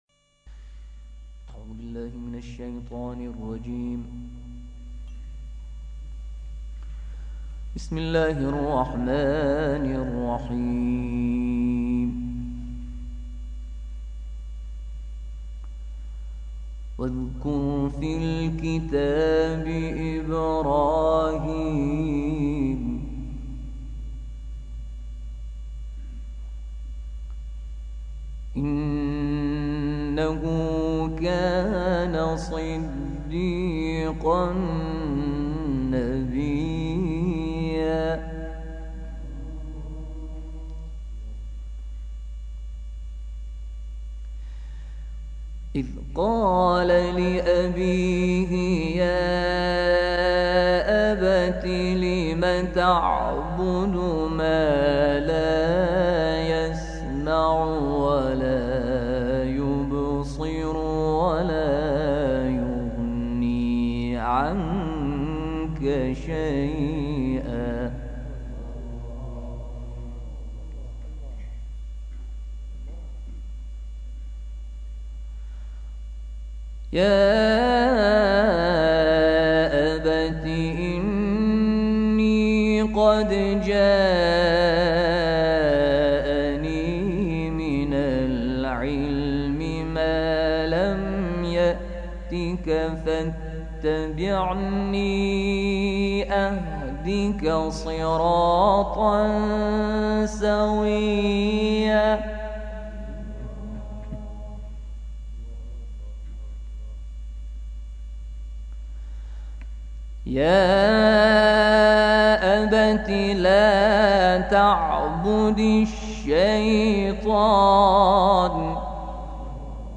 گروه فعالیت‌های قرآنی: پنج تلاوت قرآن کریم از پنج قاری بین‌المللی را بشنوید و تلاوت محبوب خود را در بخش نظرسنجی سایت ایکنا انتخاب کنید.